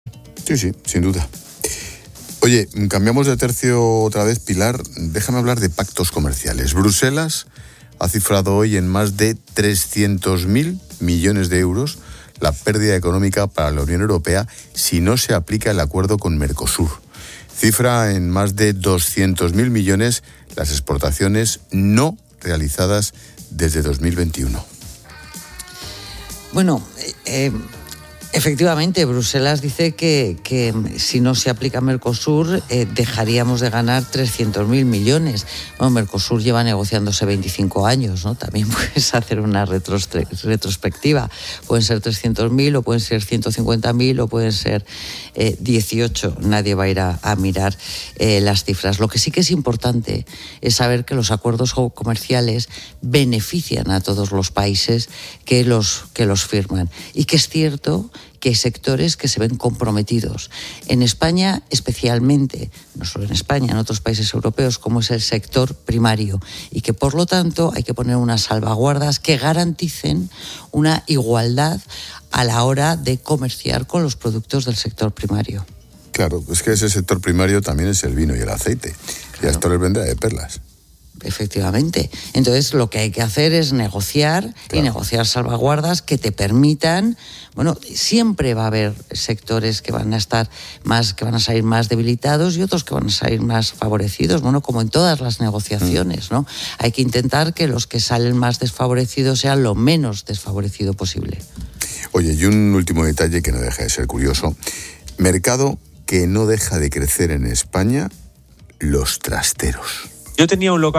Expósito aprende en Clases de Economía de La Linterna con la experta económica y directora de Mediodía COPE, Pilar García de la Granja, sobre el pacto comercial entre la Unión Europea y Mercosur